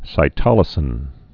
(sī-tŏlĭ-sĭn)